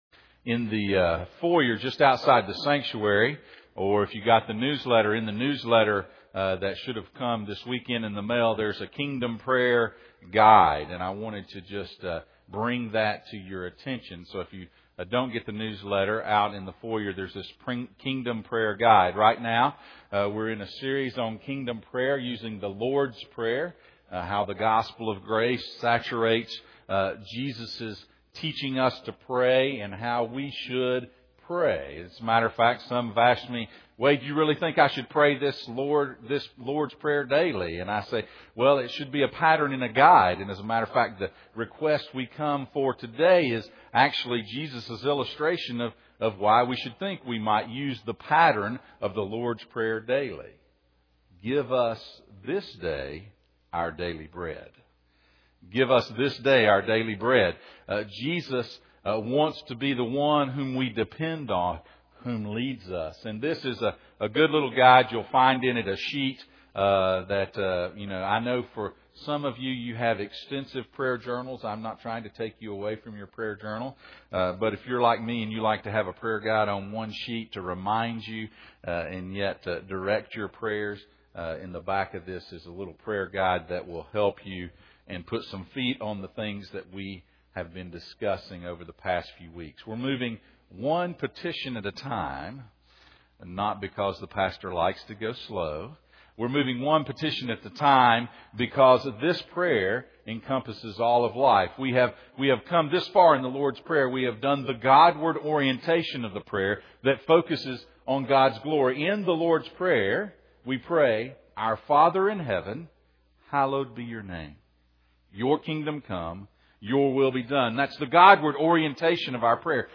Passage: Matthew 6:11 Service Type: Sunday Morning « Kingdom Prayer